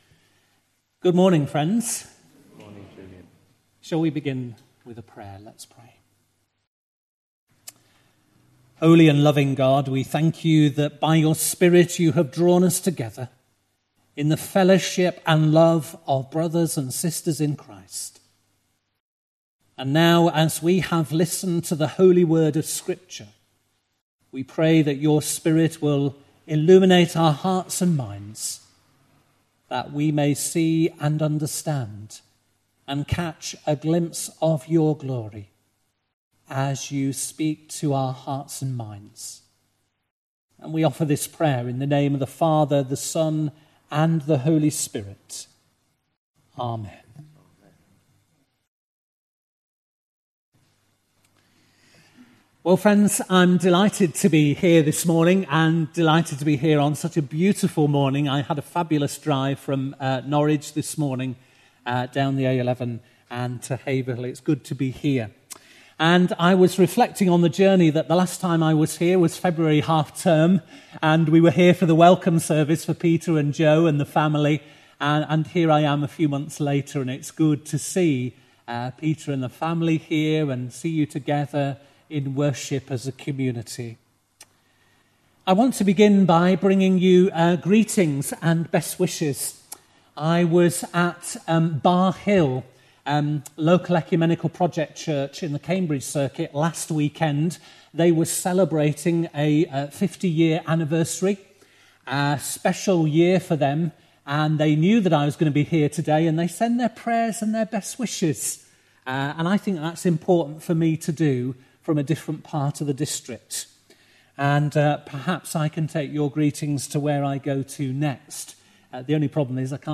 During this service we celebrate the 30th anniversary of the Next Door Coffee Shop & Drop-in.